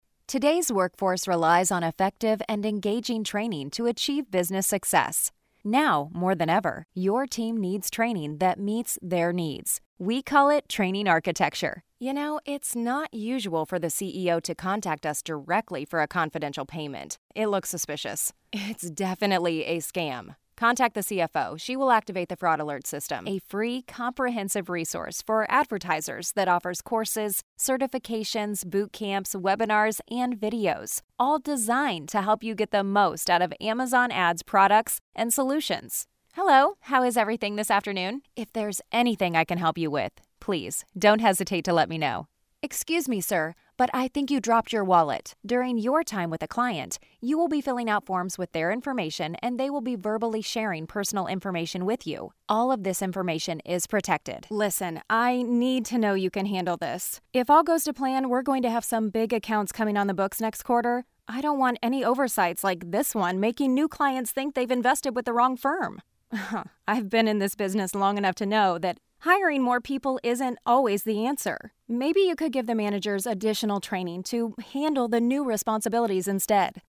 Female
My voice is youthful, upbeat, conversational and relatable.
E-Learning
E-Learning Demo
Words that describe my voice are Engaging, Conversational, Relatable.